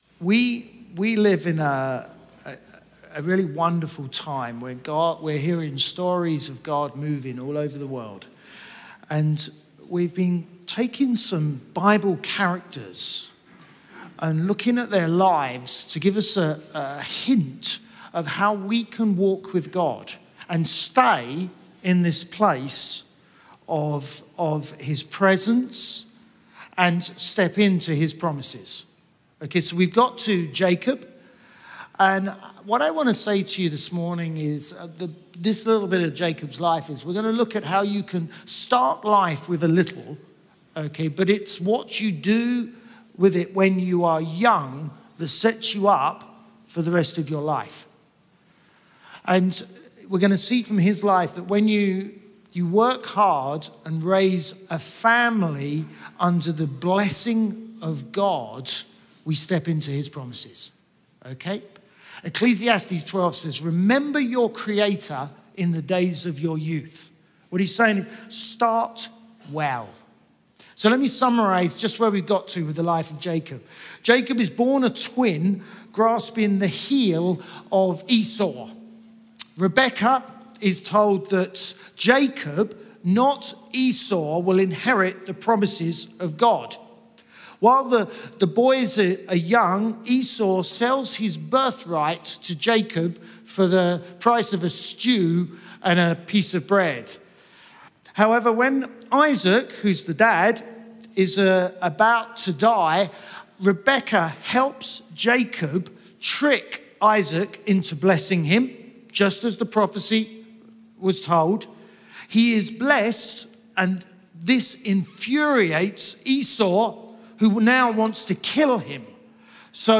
Chroma Church - Sunday Sermon Jacob starting life well Mar 03 2023 | 00:33:14 1x 00:00 / 00:33:14 Subscribe Share RSS Feed Share Link Embed